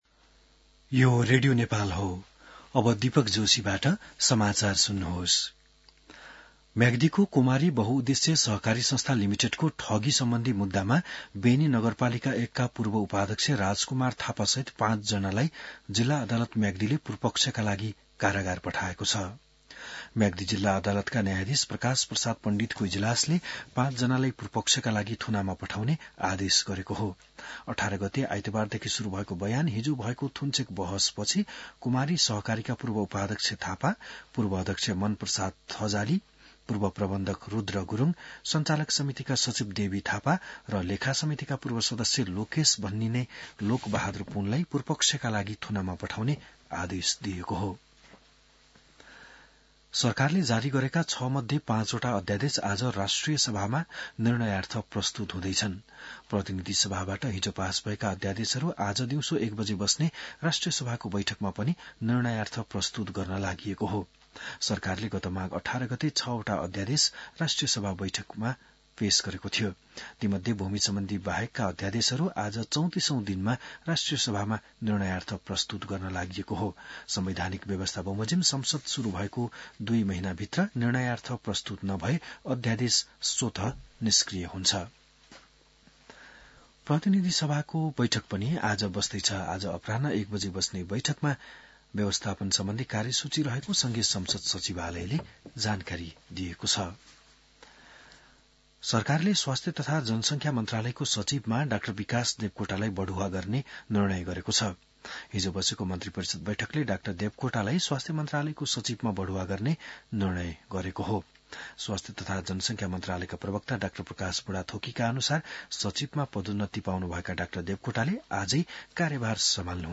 बिहान १० बजेको नेपाली समाचार : २३ फागुन , २०८१